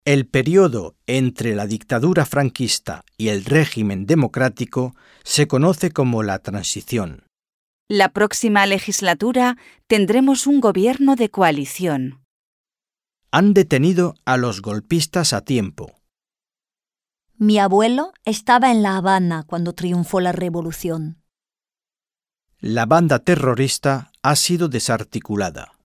Un peu de conversation - L'état